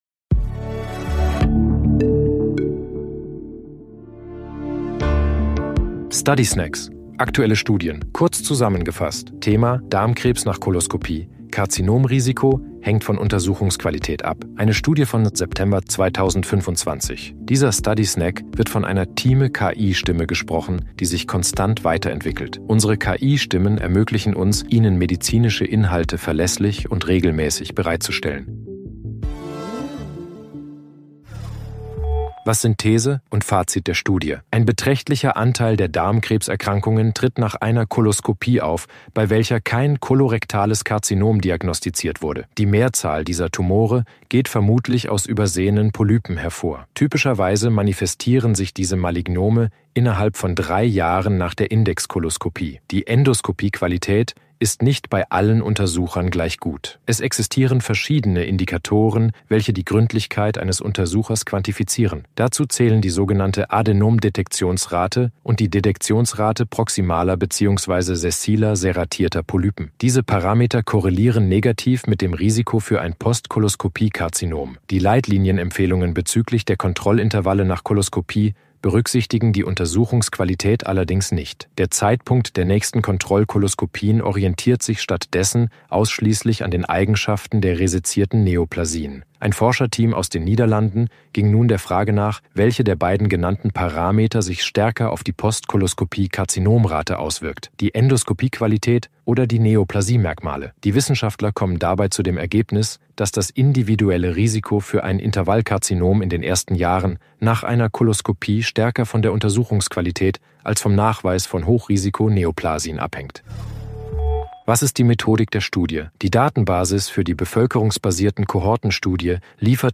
sind mit Hilfe von künstlicher Intelligenz (KI) oder maschineller
Übersetzungstechnologie gesprochene Texte enthalten